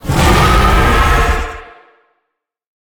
Sfx_creature_hiddencroc_alert_02.ogg